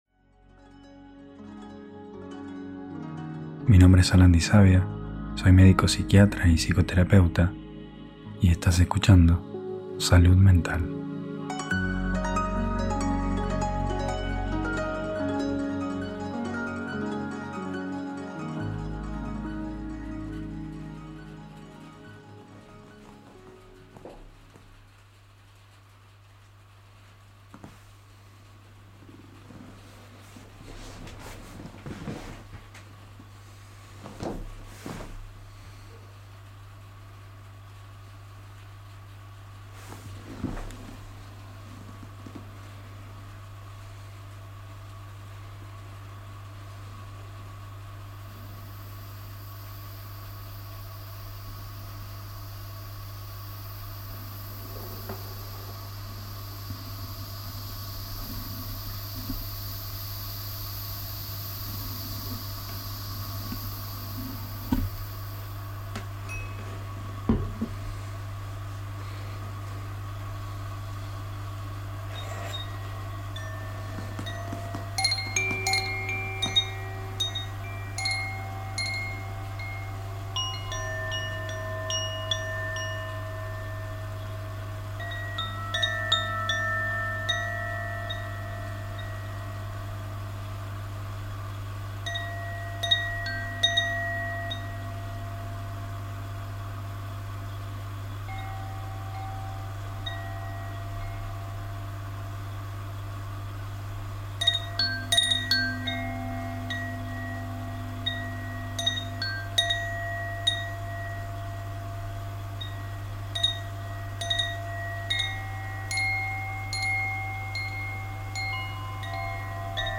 Ambiente de laboratorio para estudiar, meditar o dormir ⚗‍✨
El sonido del laboratorio es el ritmo suave de la ciencia en movimiento.Silencio vivo.Grabado en mi espacio de estudio, en plena madrugada.Ideal para estudiar, meditar o dormir..Para citas y consultas